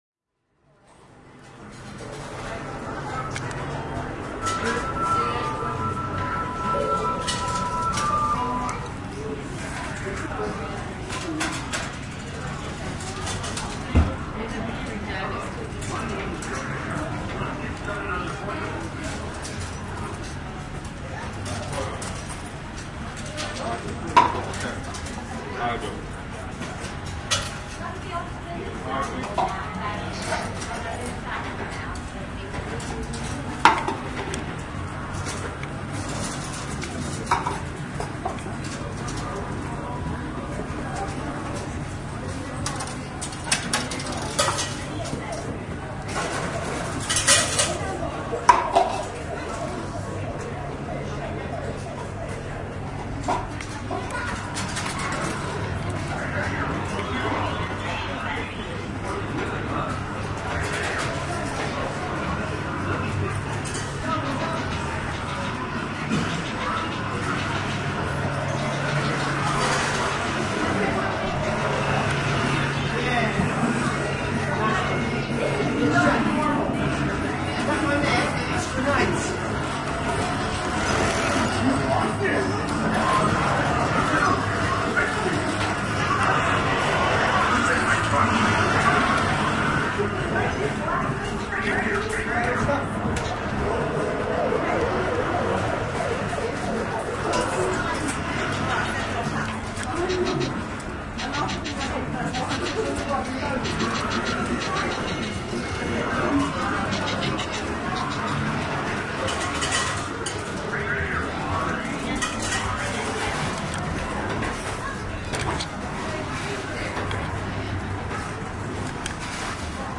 气氛 " 便士商场
描述：一分钱的气氛，游乐场
Tag: 娱乐 商场 插槽 大气